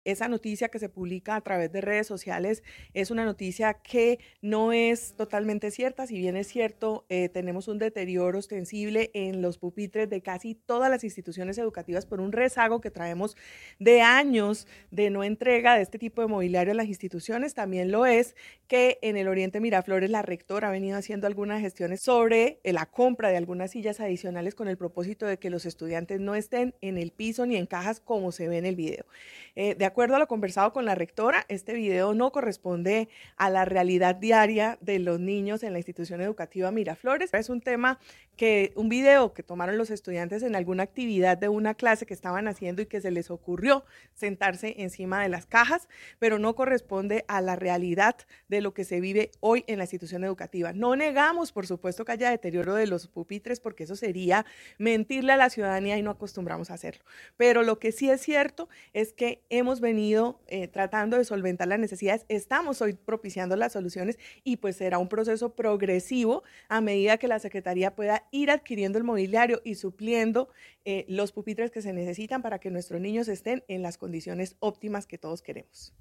Martha Guarín, Secretaria de Educación de Bucaramanga